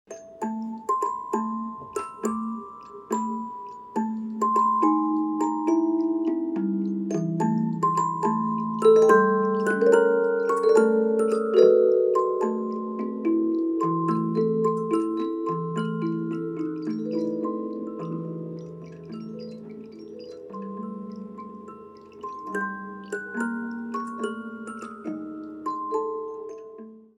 une boîte à musique artisanale
avec 1200 cylindres et clavier 72 notes